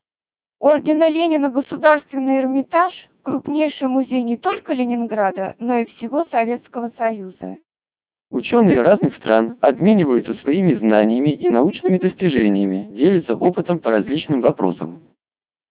• CODEC2 2400 bps vocoder, on average, also falls within the "Fair" category (70-85%), but tends to remain in its lower range. For some languages, its intelligibility may even fall into the "Poor" category (50-70%).
Speech Samples (WAV-files).
You can listen to short samples of the original speech as well as the processed speech from these vocoders in any of the 20 languages through the links in the table below.